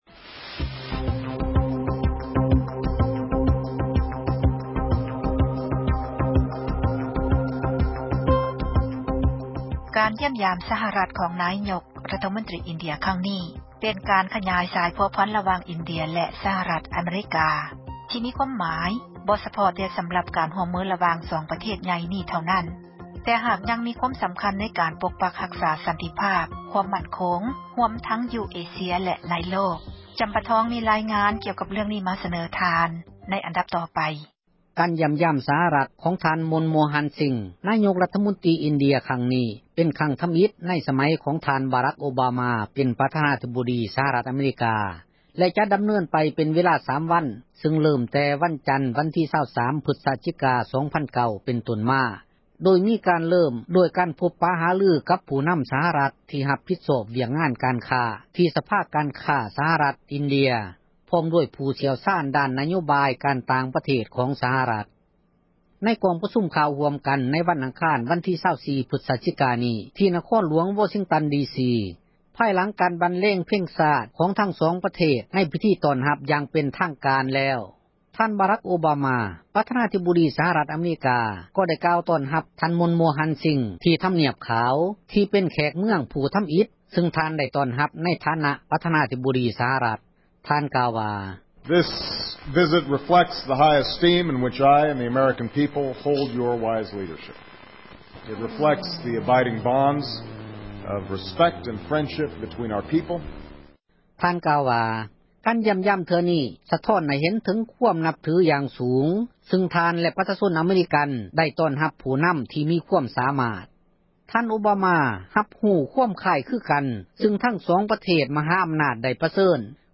ນາຍົກຣັຖມົນຕຣີອິນເດັຽ ຢ້ຽມຢາມສະຫະຣັດ — ຂ່າວລາວ ວິທຍຸເອເຊັຽເສຣີ ພາສາລາວ